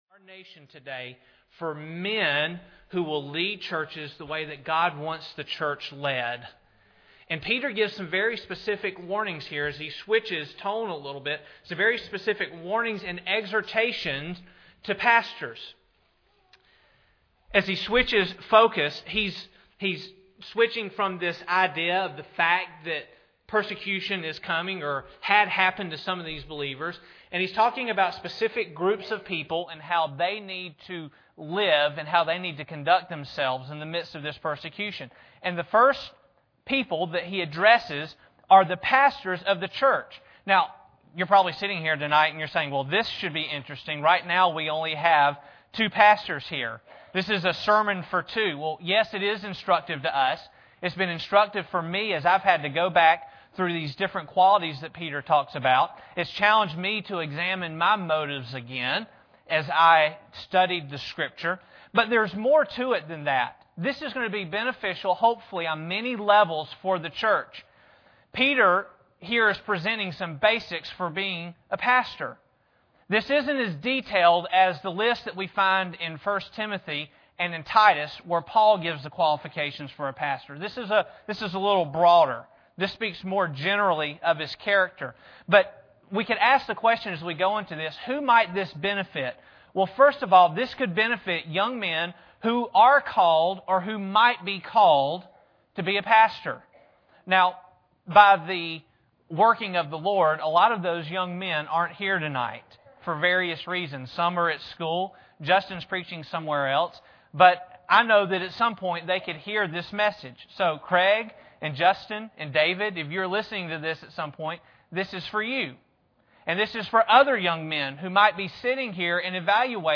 Preacher
Service Type: Sunday Evening